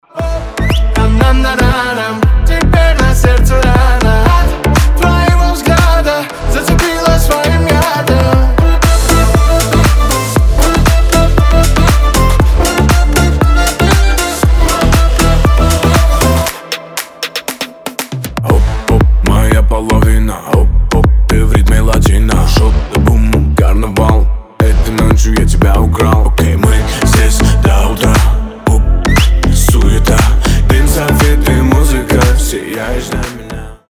• Качество: 320, Stereo
свист
зажигательные